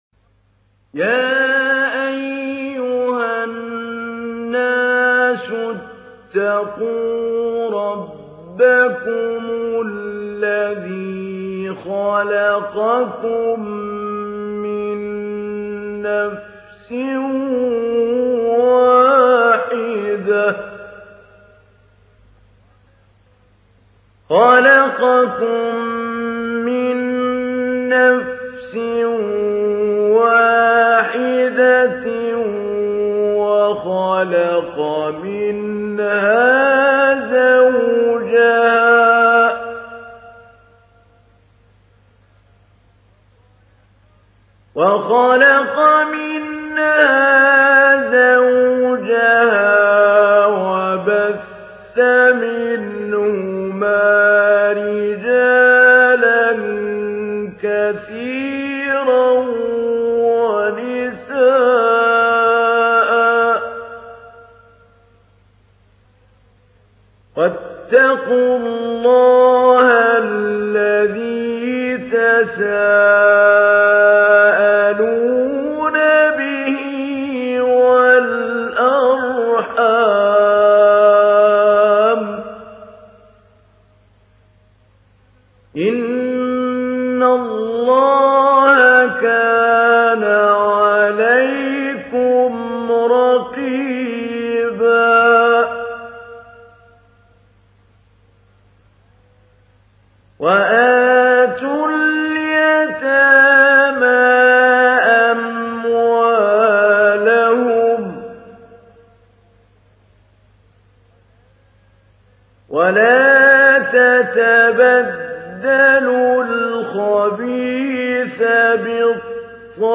تحميل : 4. سورة النساء / القارئ محمود علي البنا / القرآن الكريم / موقع يا حسين